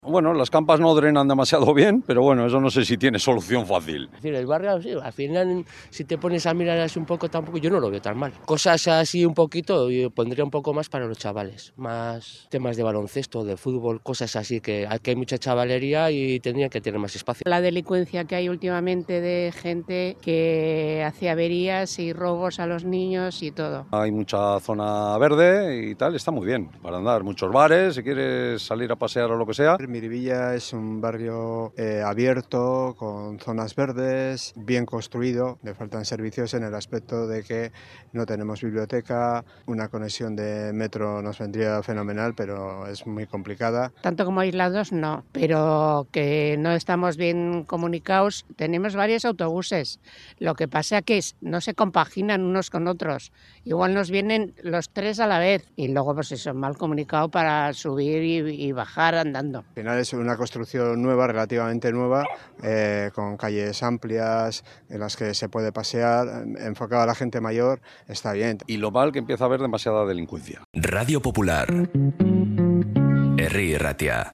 En 'Bilbao al habla' conocemos la opinión de los vecinos de Miribilla